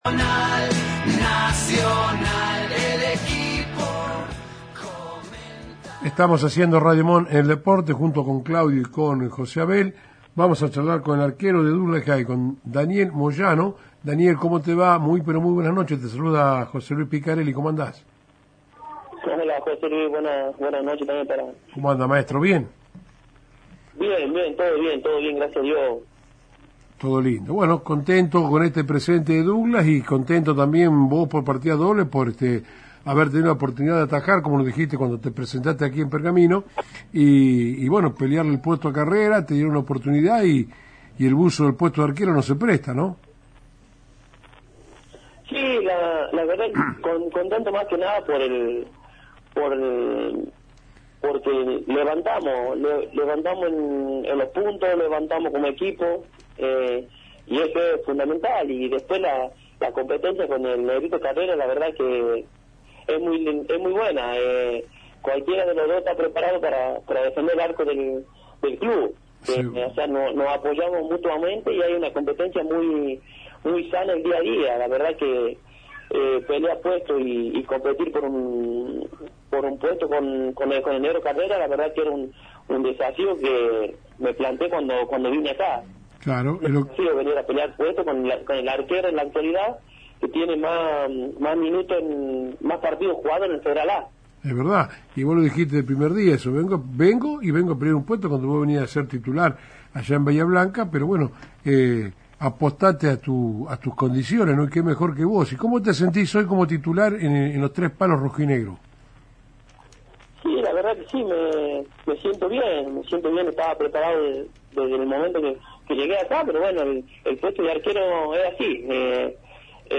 En una reciente entrevista en el programa deportivo Radiomón en el Deporte